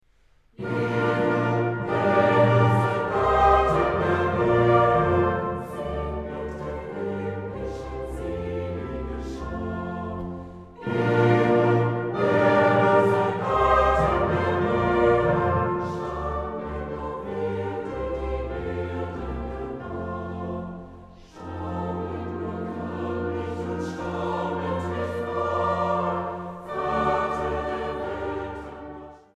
Feierstunde in Berlin-Lichtenberg am 6. November 2010